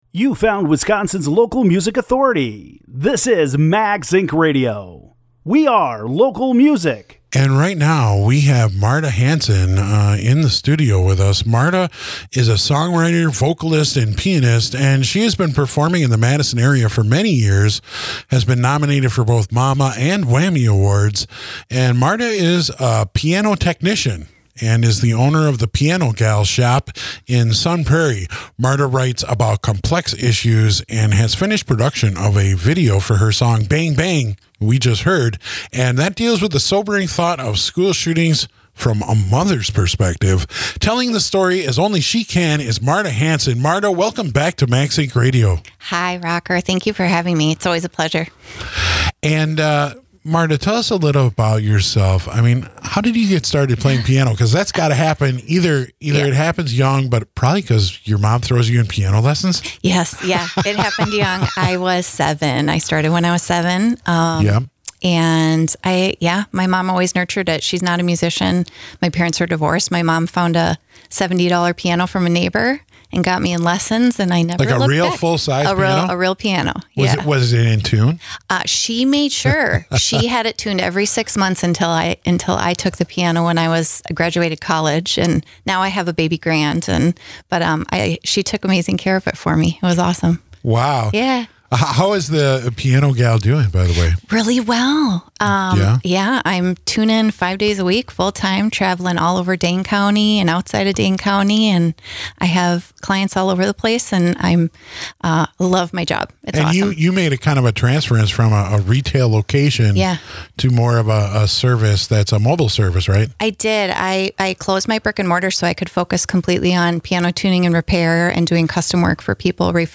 spinning Wisconsin-made music plus interviews with local artists and live performances.